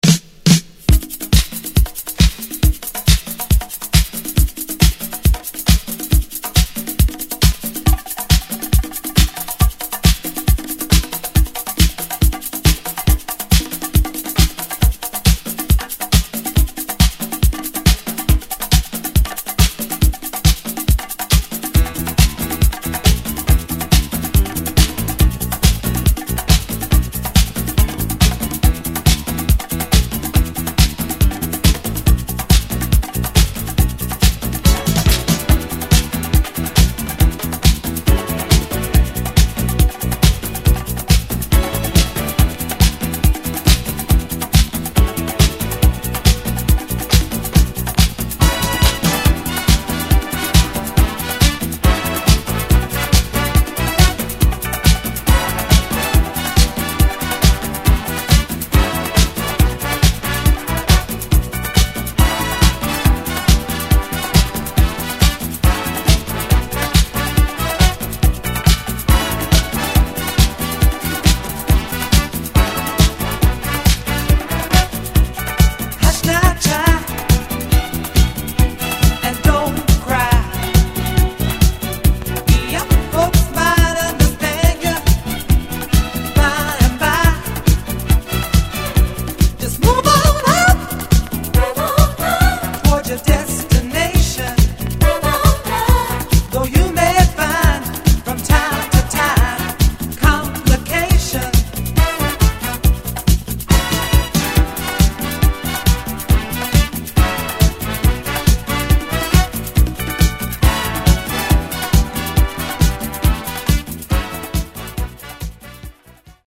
・ DISCO 70's 12'